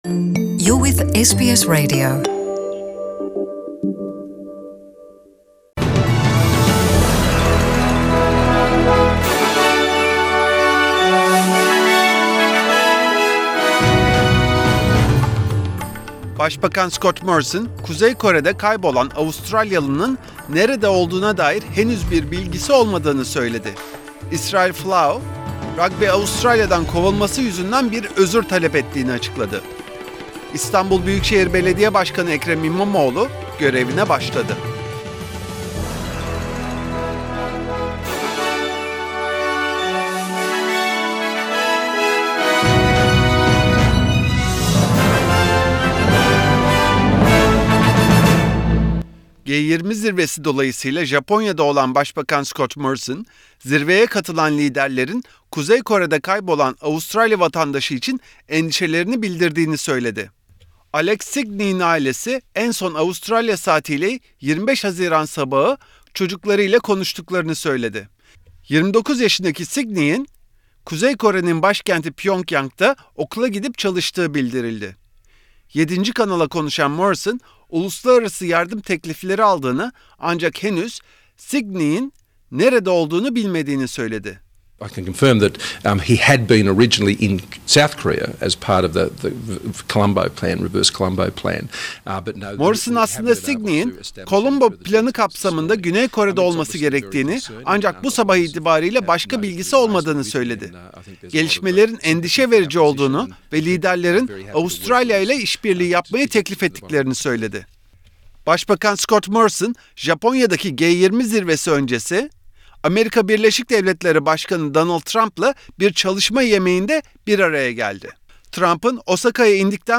SBS Türkçe Haberler